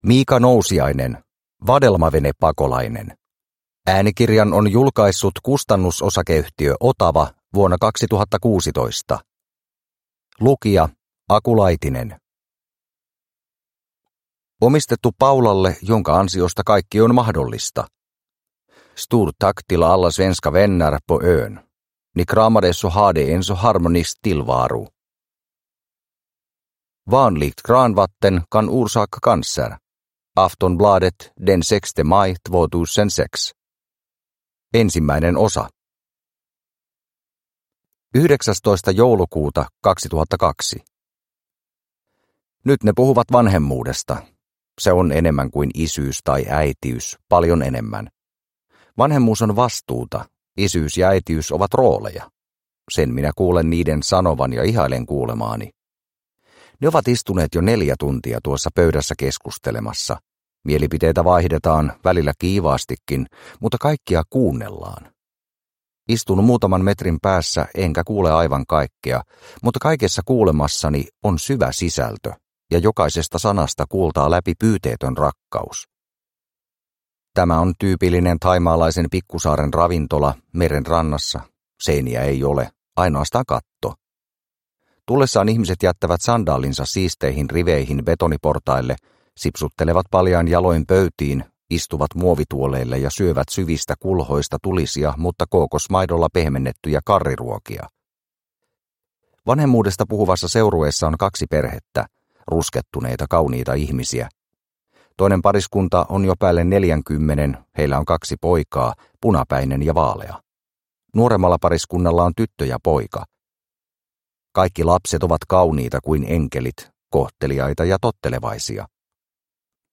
Vadelmavenepakolainen – Ljudbok – Laddas ner